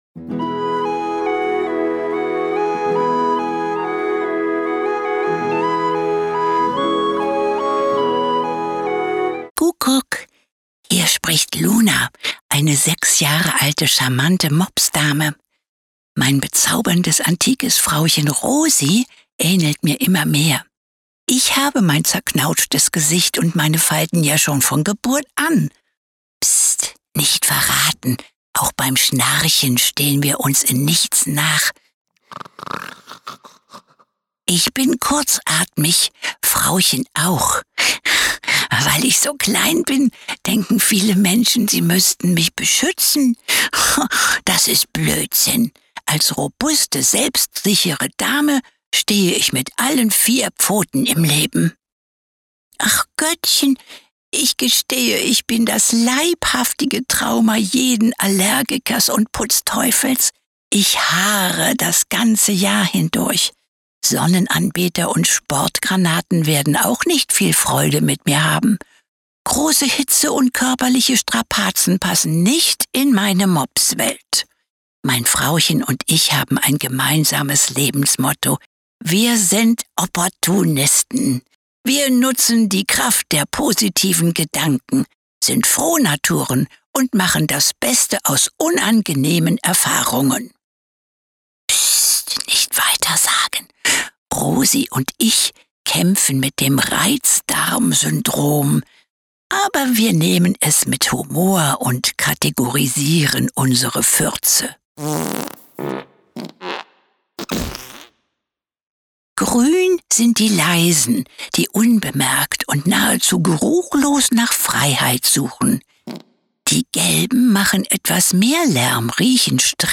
Schauspielerin & Synchronsprecherin.